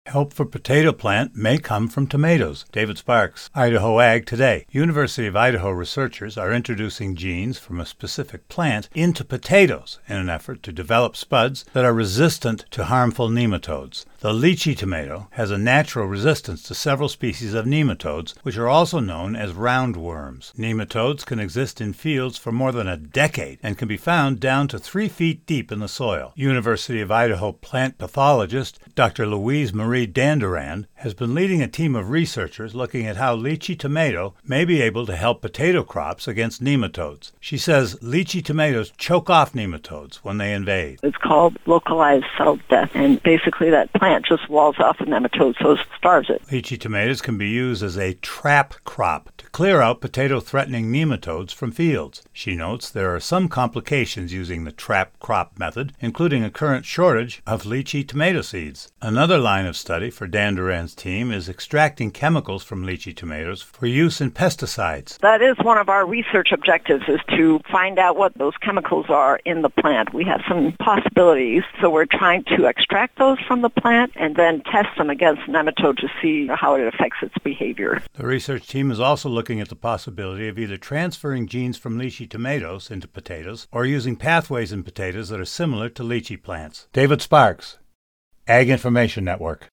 University of Idaho researchers are introducing genes from a specific plant into potatoes in an effort to develop spuds that are resistant to harmful nematodes. The Litchi [LEE-chee] tomato has a natural resistance to several species of nematodes, which are also known as round worms.